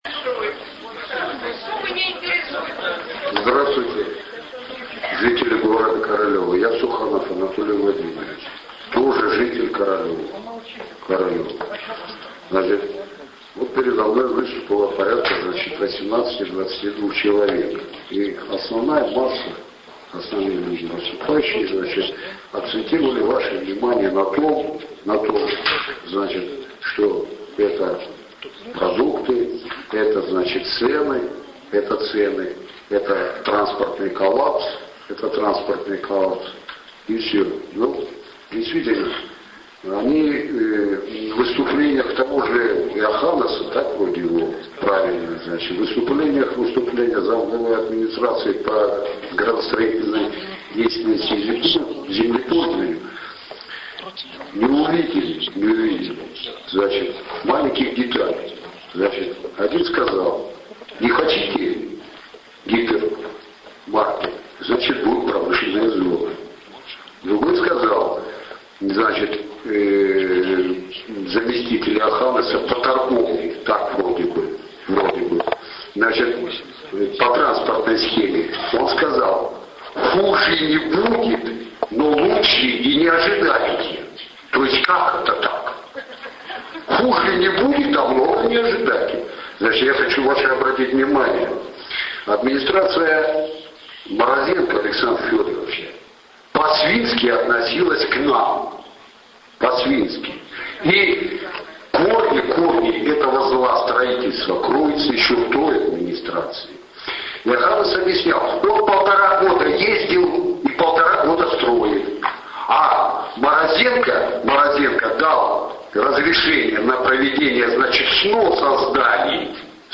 Слушания по Глобусу